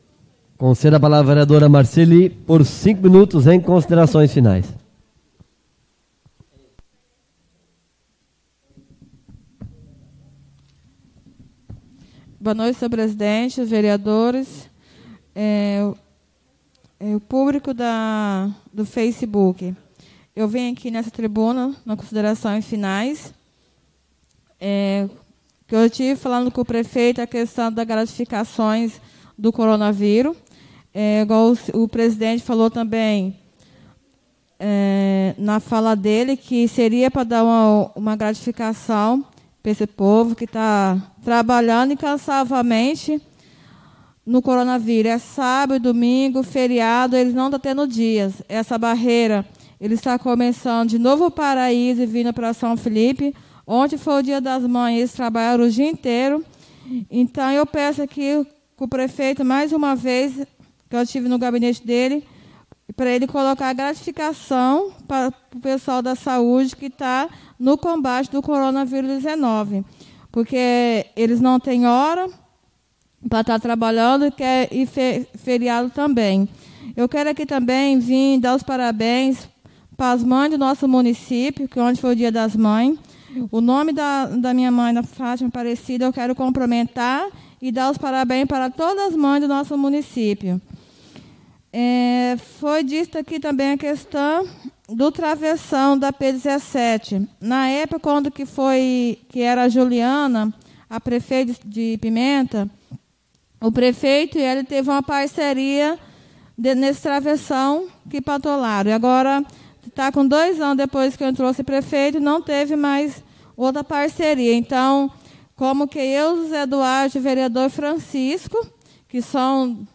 Oradores das Explicações Pessoais (13ª Ordinária da 4ª Sessão Legislativa da 6ª Legislatura)